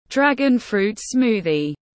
Sinh tố thanh long tiếng anh gọi là dragon fruit smoothie, phiên âm tiếng anh đọc là /’drægənfru:t ˈsmuː.ði/
Dragon fruit smoothie /’drægənfru:t ˈsmuː.ði/